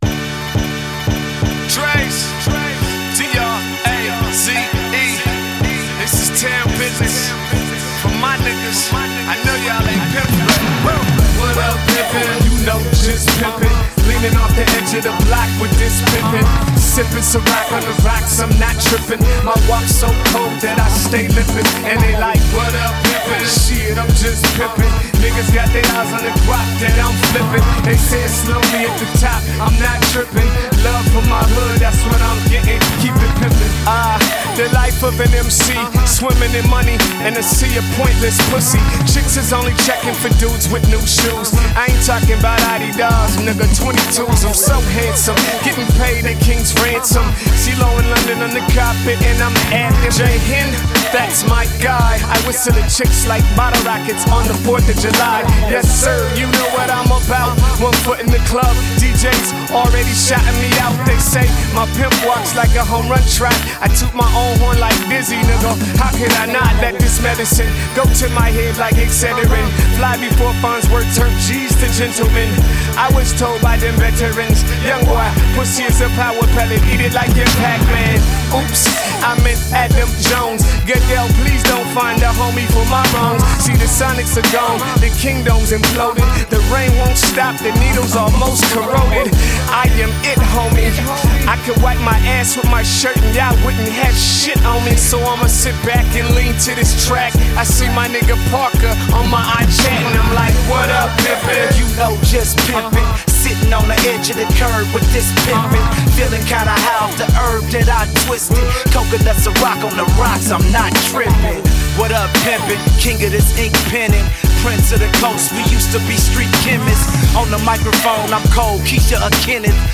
a textbook posse cut
It’s bombastic and cocky